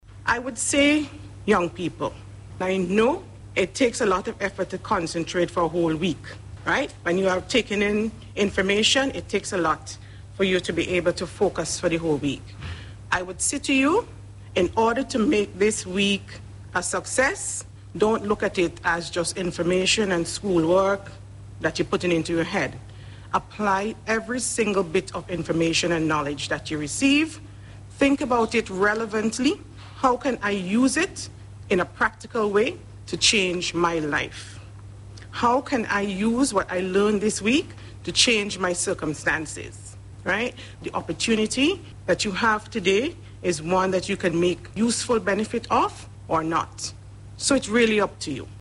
Speaking at the opening ceremony yesterday